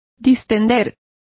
Complete with pronunciation of the translation of defused.